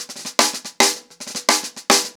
TheQuest-110BPM.21.wav